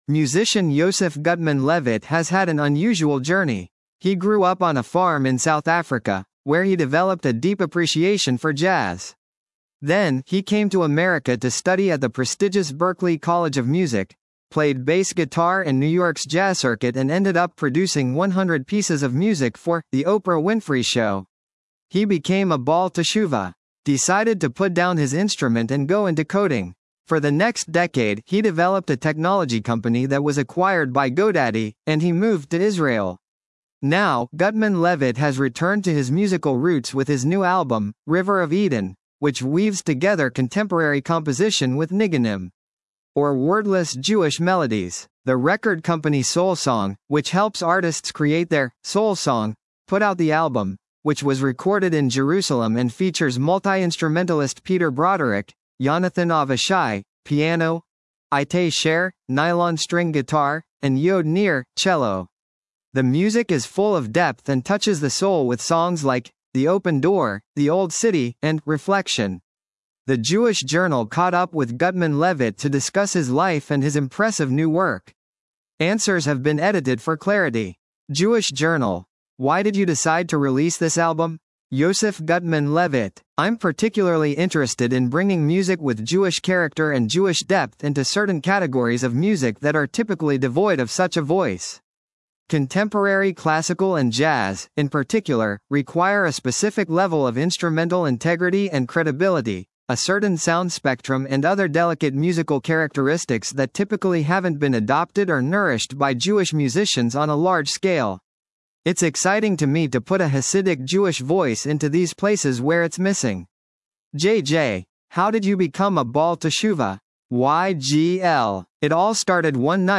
recorded in Jerusalem
piano
nylon-string guitar
cello